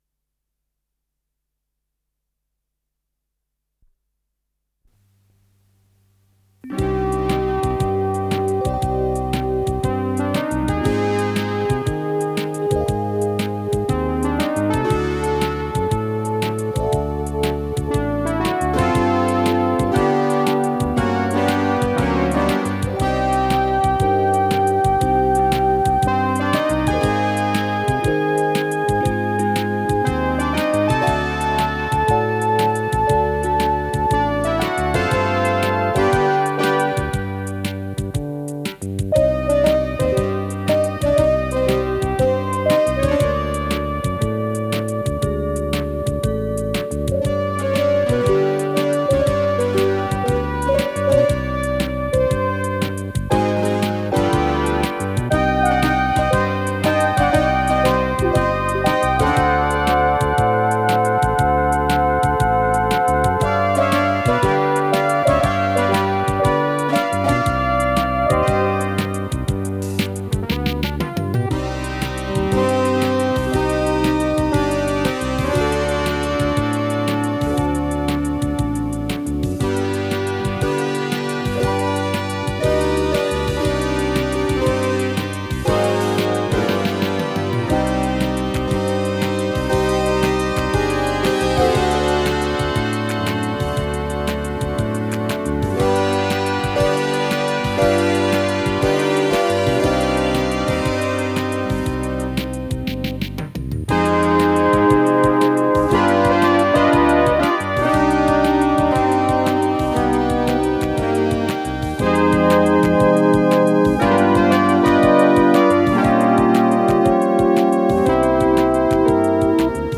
с профессиональной магнитной ленты
синтезатор
Скорость ленты38 см/с
Тип лентыORWO Typ 106